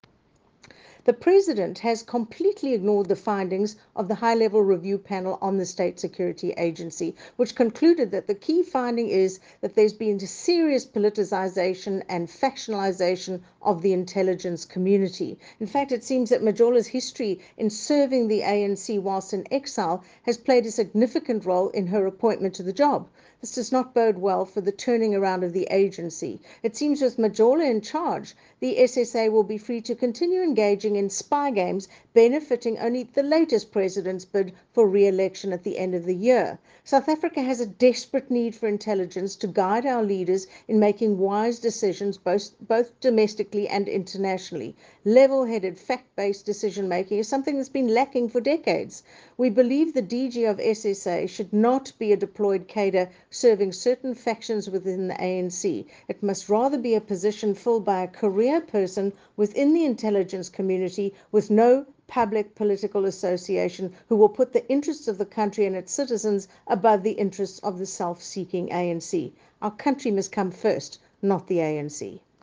soundbite by Dianne Kohler Barnard MP.